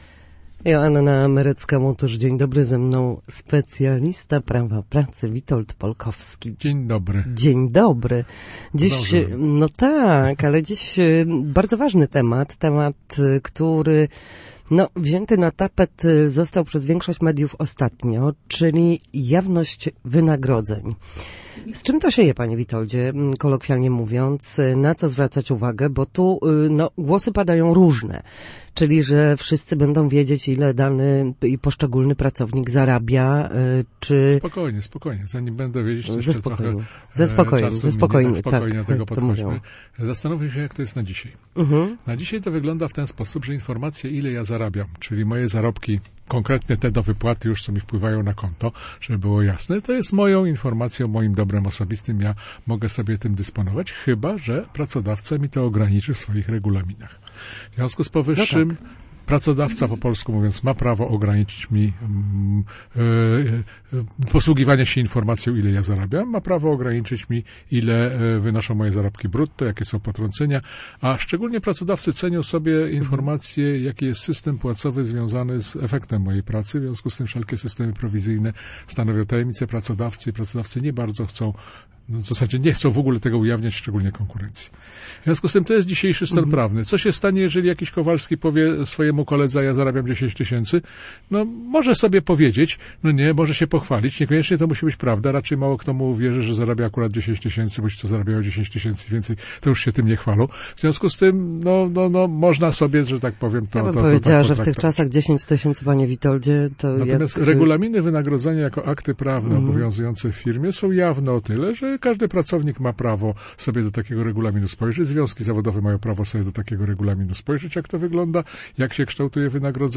Jawność wynagrodzeń i likwidacja luki płacowej. Rozmawiamy o równości w zarobkach